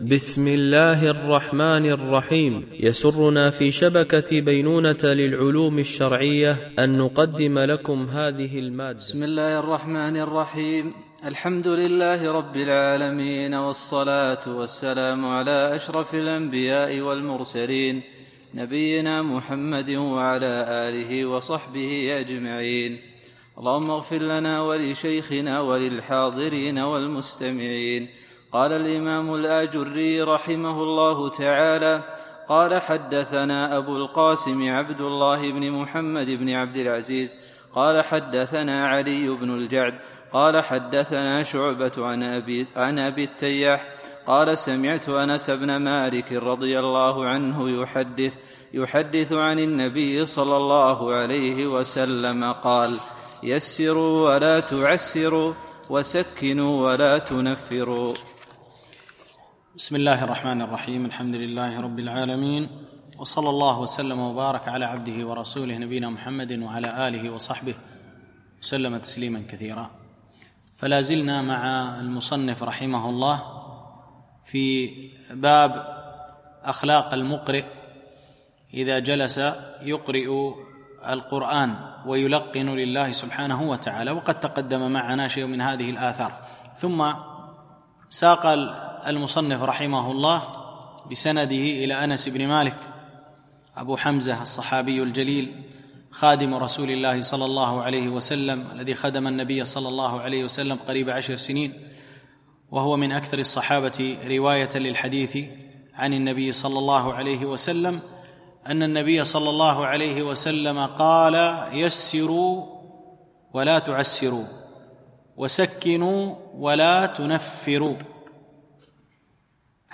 شرح آداب حملة القرآن ـ الدرس 8